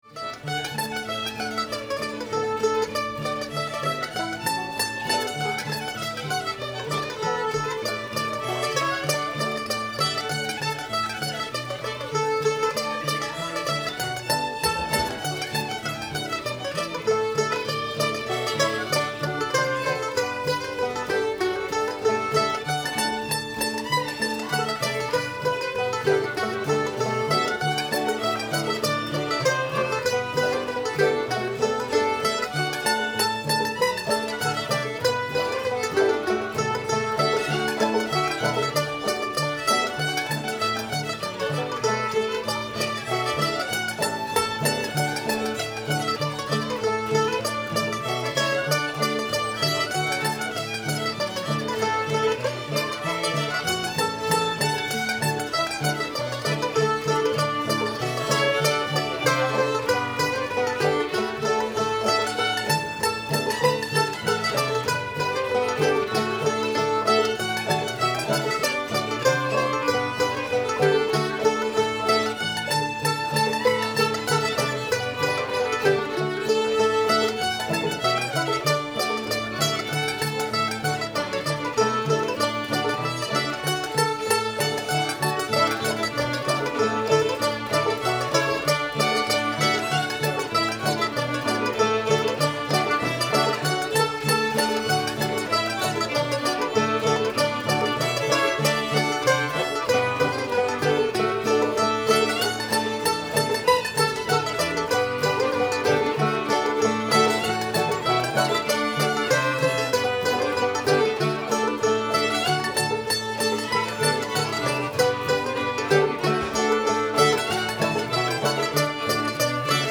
moses hoe your corn [D]